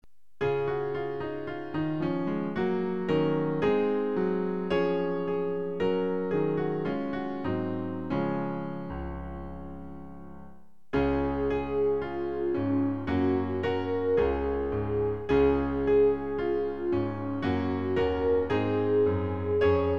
Themenbereich: Jesuslieder Strophen: 3 Länge: 01:22